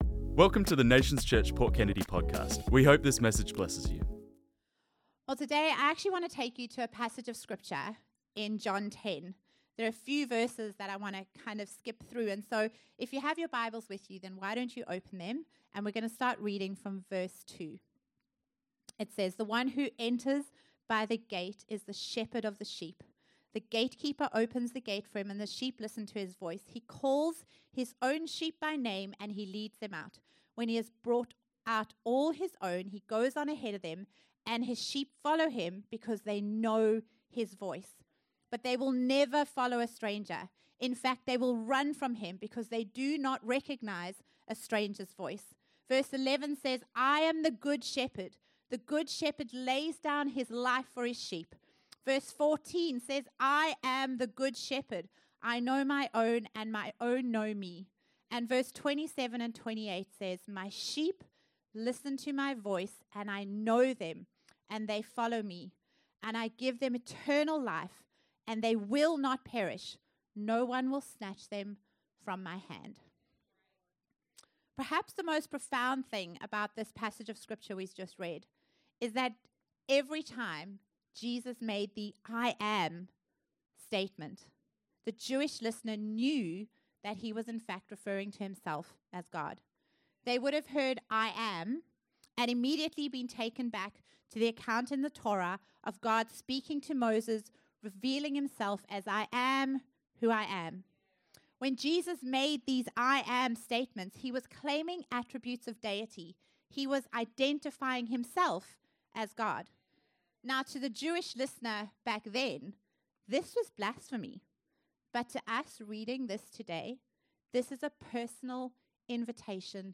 This message was preached on Sunday 11th May 2025